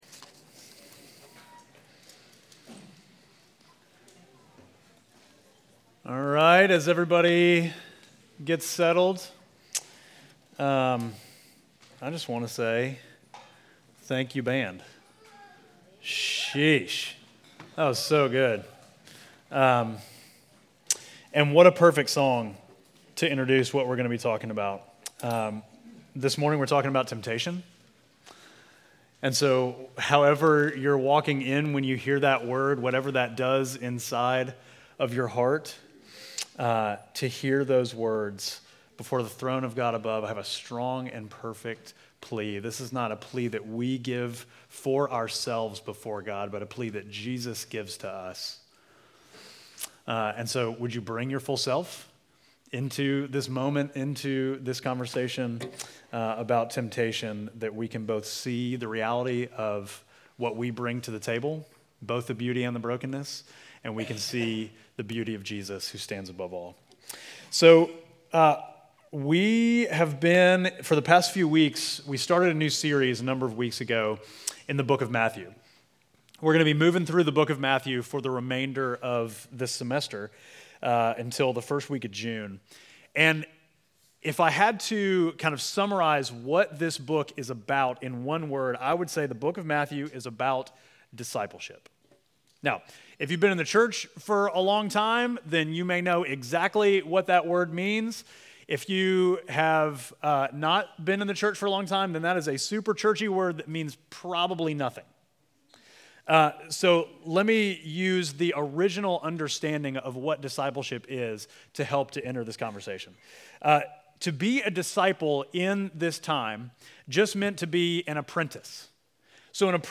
Midtown Fellowship Crieve Hall Sermons Temptation Feb 25 2024 | 00:44:22 1x 00:00 / 00:44:22 Subscribe Share Apple Podcasts Spotify Overcast RSS Feed Share Link Embed